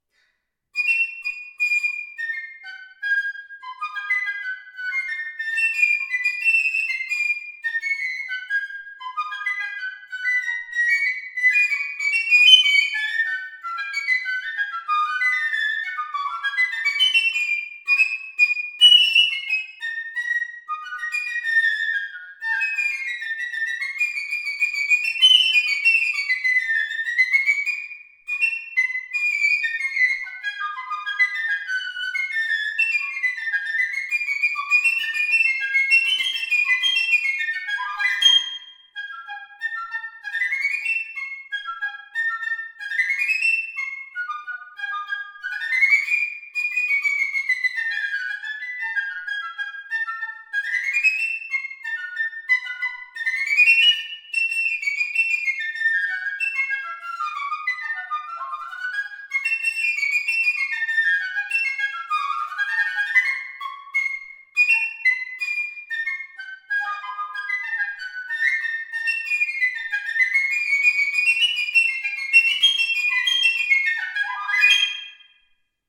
Description Ebony Bühner & Keller piccolo with additional corps de rechange
Listen to this piccolo playing Piccolo-Polka by Eugène DAMARÉ (1840-1919).
Now it plays very nicely indeed with excellent intonation. To top that, it has a bright and colourful sound. Pitch??? – a=442 Hz!
PICCOLO-BUHNER-KELLER-Eugene-Damare-Piccolo-Polka.mp3